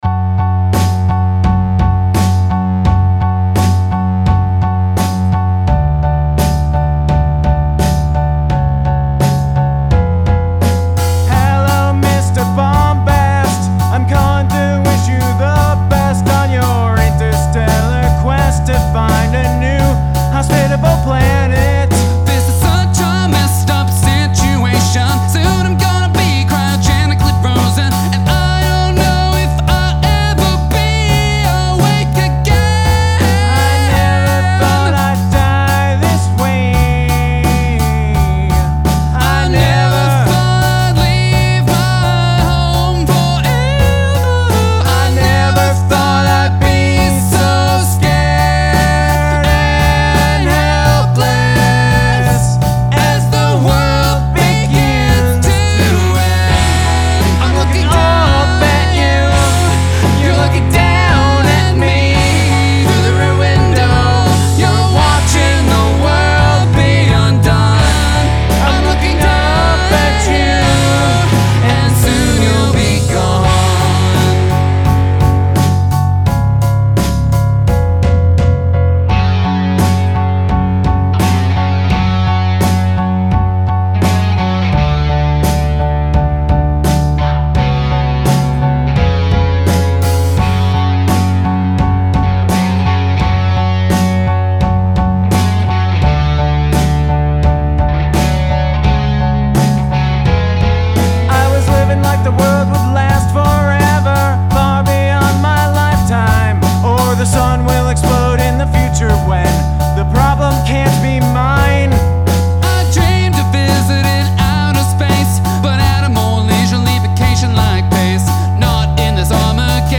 DYNAMICS/MIX: [GOOD] 2 points